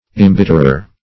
Imbitterer \Im*bit"ter*er\, n.